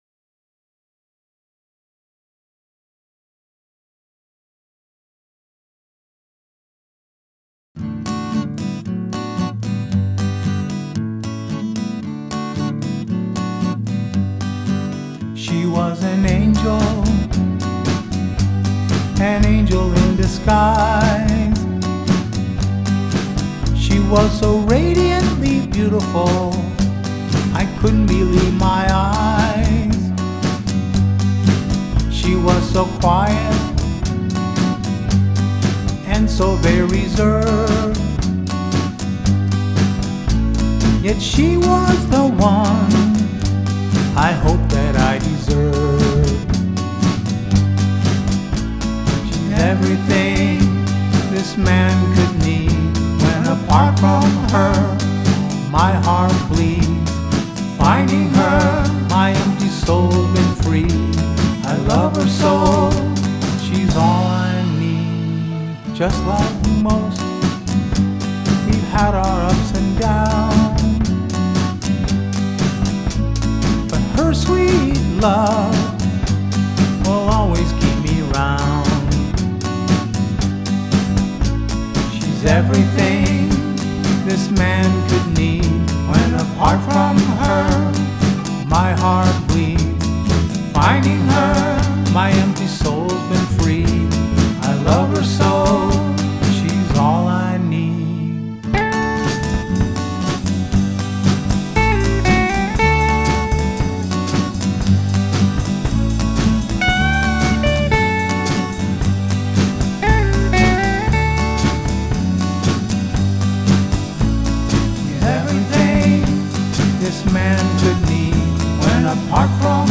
Original Songs